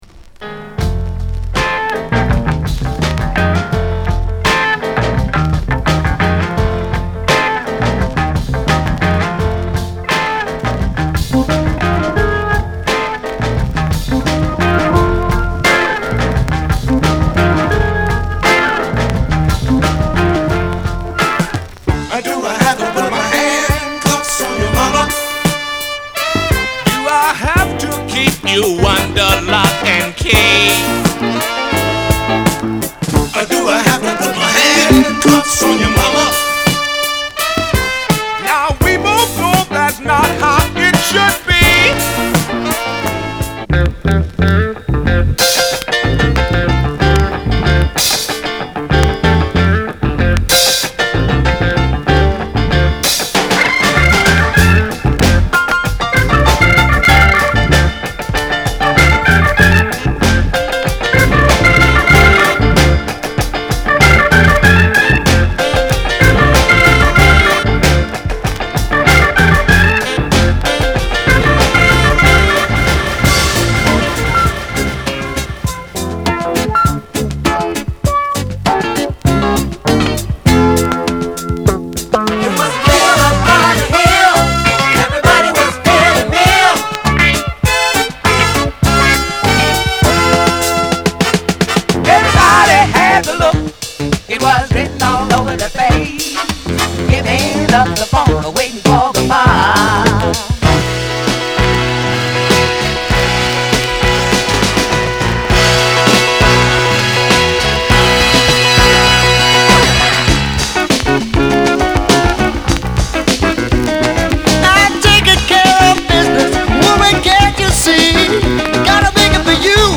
category R&B & Soul